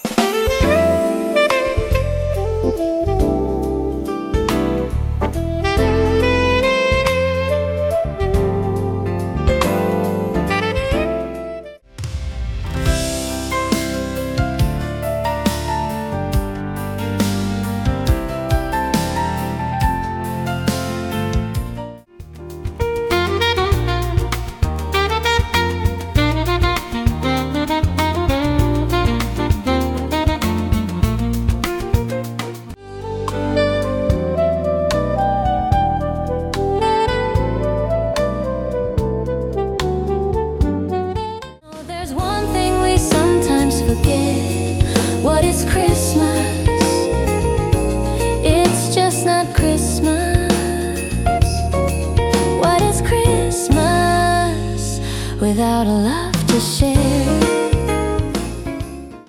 Step into the world of Oregon pianist
Warm, timeless, and beautifully arranged